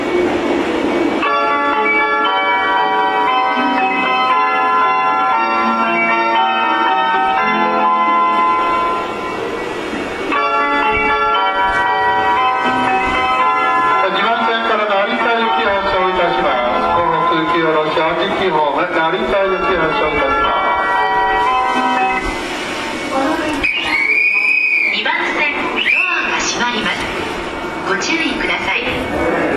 発車メロディをかき消してくれます。
ドアが開いた瞬間に発車メロディが鳴り出すのもこの駅ならではです。